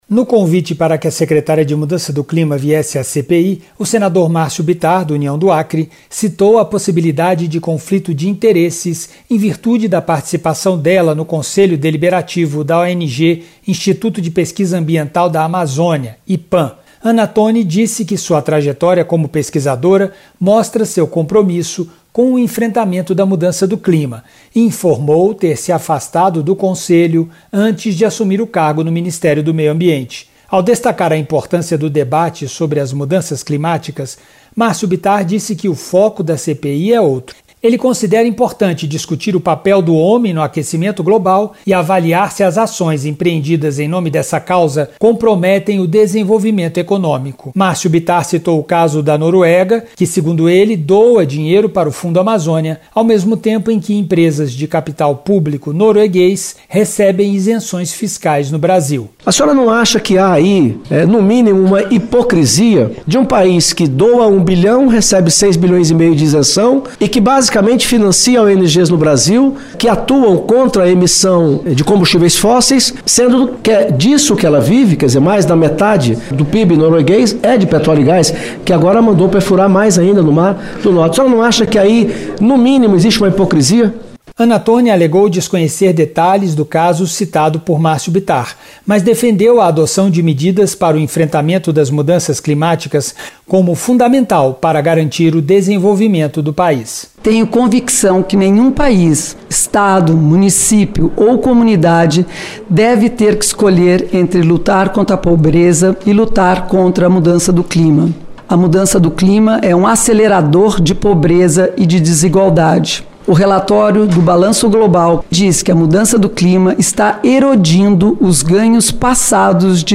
A CPI das ONGs ouviu nesta terça-feira (26) a secretária de Mudança do Clima do Ministério do Meio Ambiente, Ana Toni.